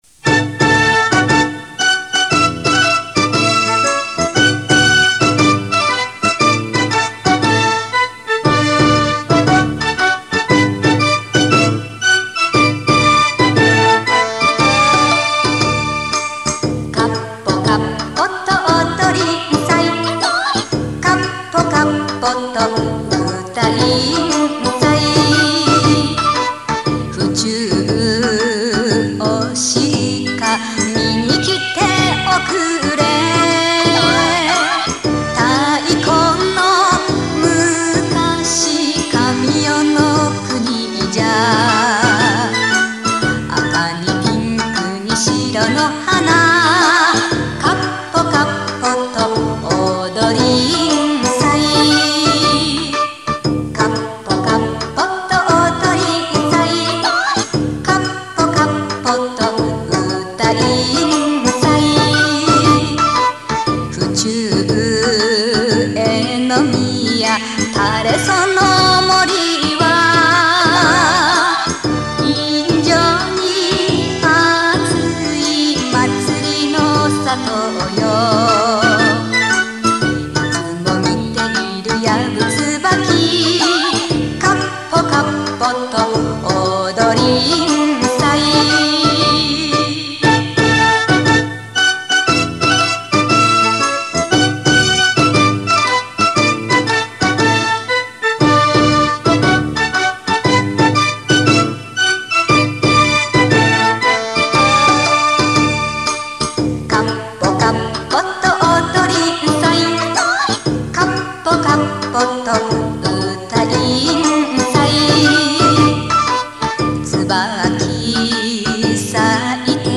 勇壮な太鼓の音に合わせて「かっぽ音頭」の踊りの輪が広がれば、祭は最高潮に達します。